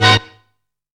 SKINNY HIT.wav